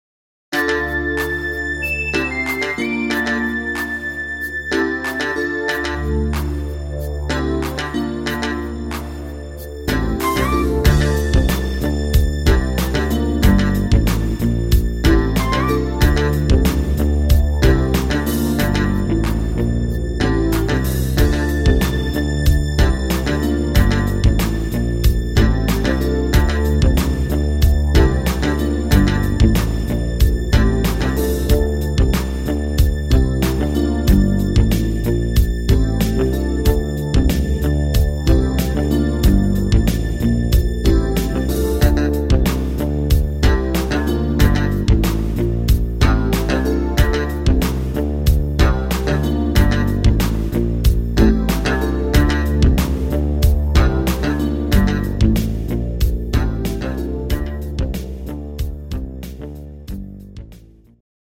Rhythmus  Pop Rap
Art  Deutsch, Pop